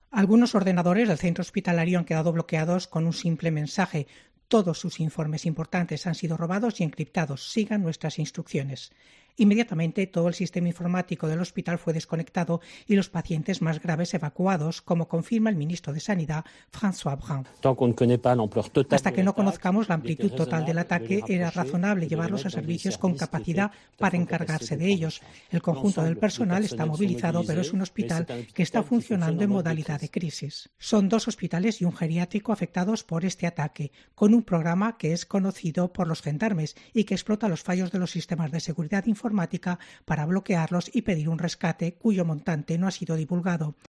Ciberataque a dos hospitales y un geriátrico en Francia. Crónica corresponsal en París